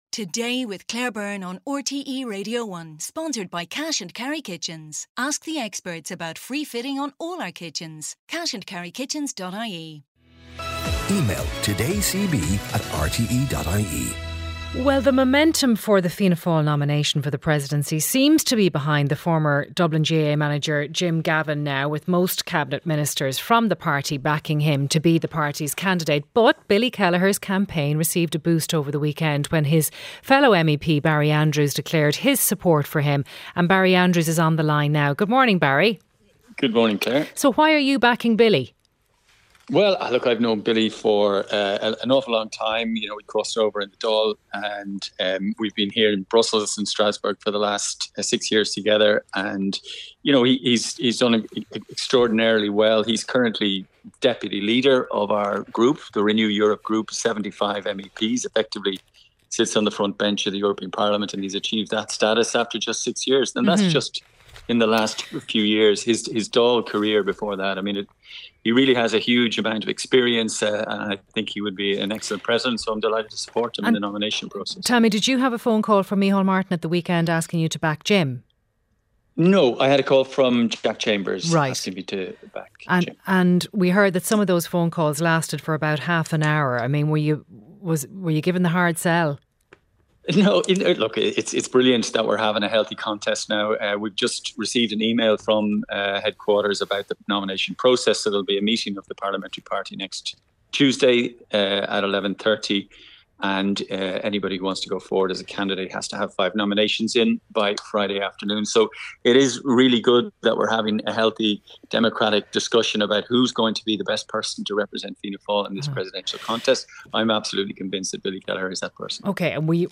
Barry Andrews, Fianna Fáil MEP for Dublin